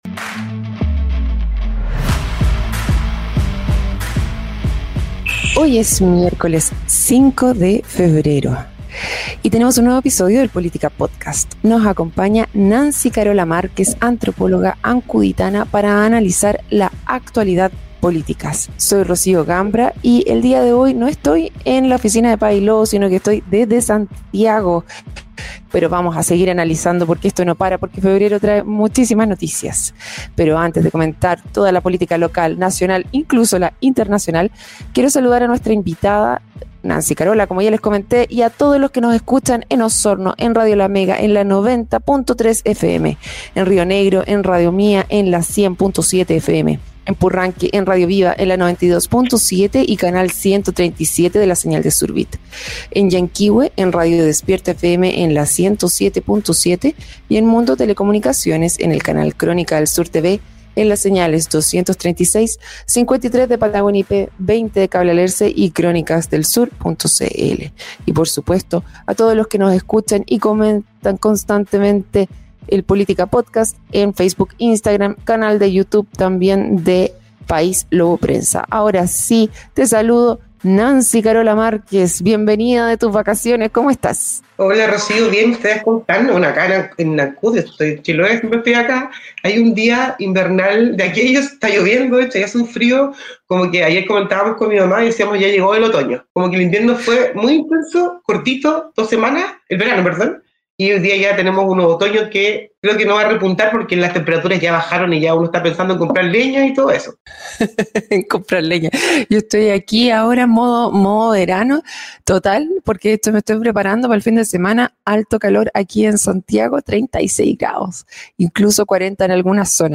La conversación giró en torno a cómo este anuncio podría cambiar el equilibrio geopolítico y la estabilidad mundial.